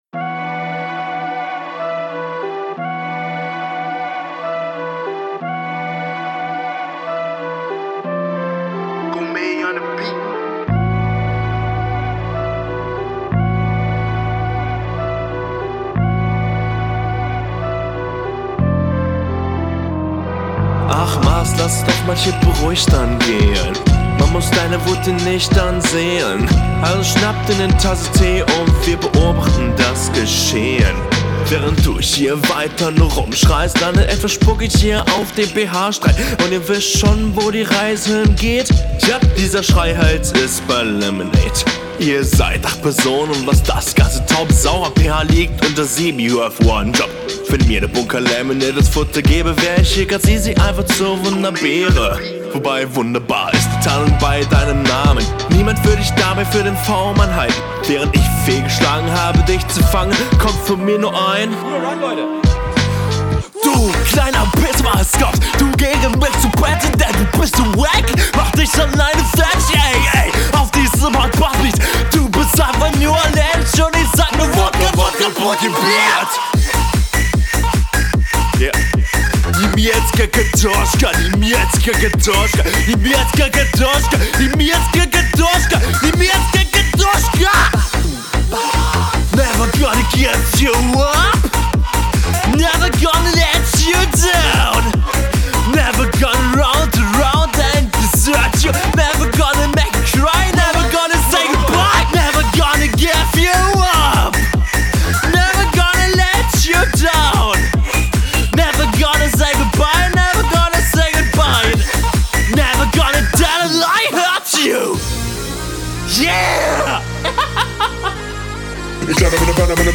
Betonungen super unnatürlich.